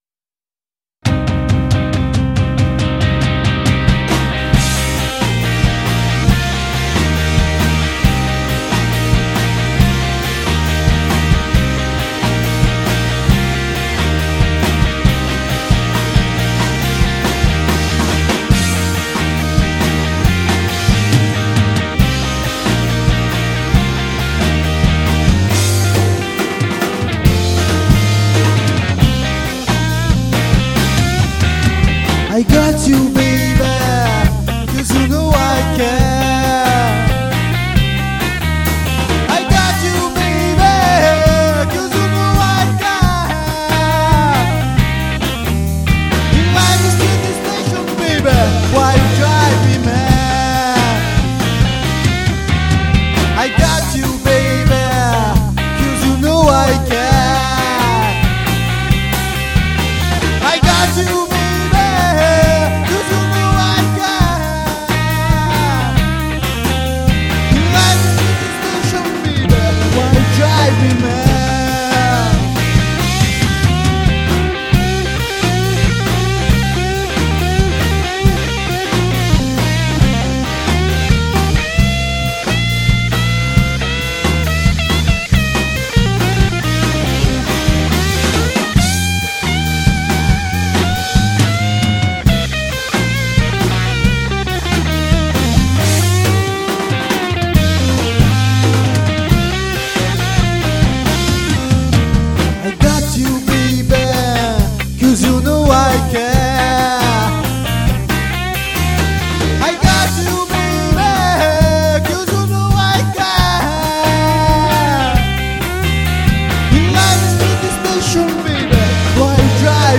1721   03:16:00   Faixa:     Rock Nacional